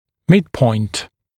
[‘mɪdpɔɪnt][‘мидпойнт]средняя точка, центр